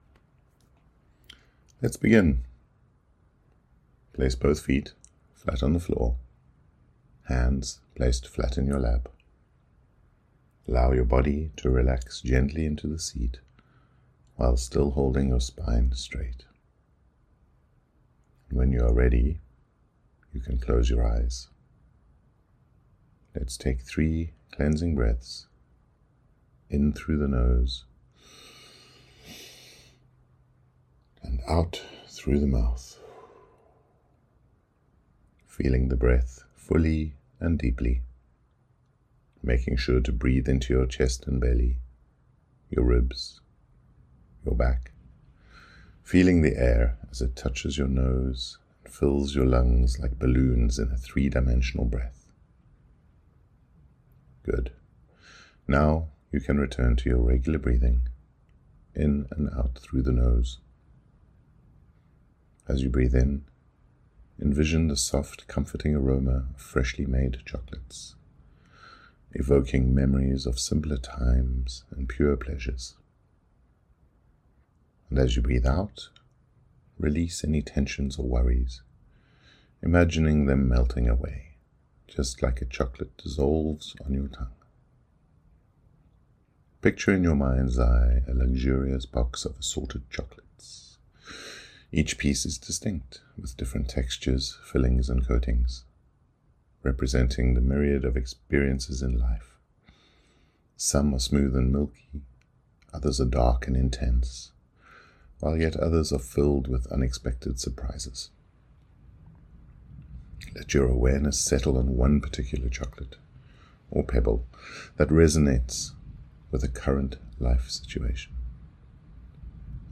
FS01-meditation-life-is-a-box-of-chocolates.mp3